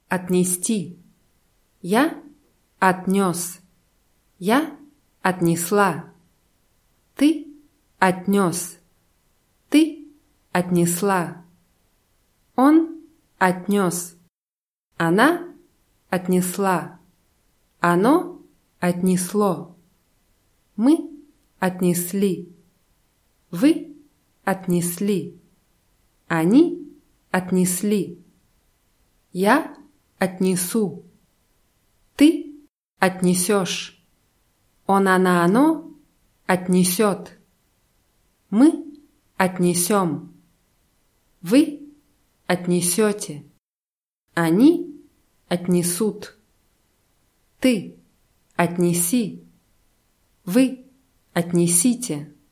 отнести [atnʲißtʲí]